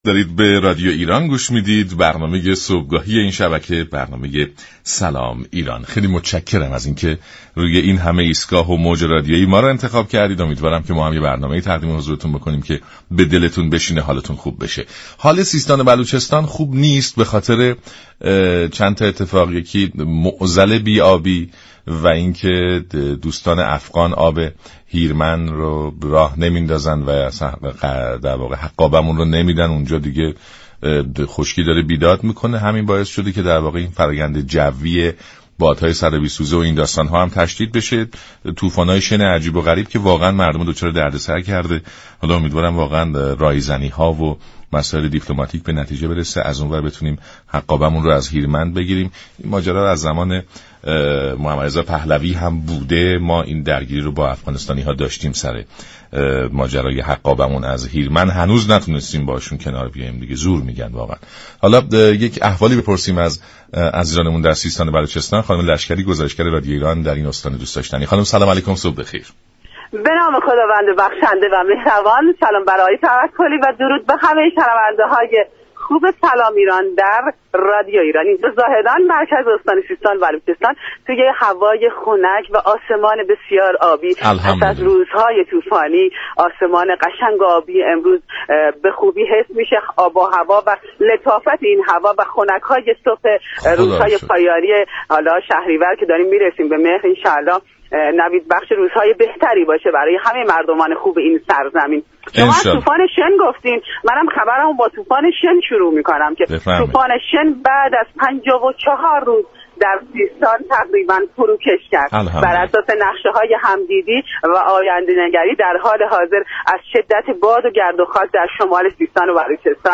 رویداد
طی ارتباط تلفنی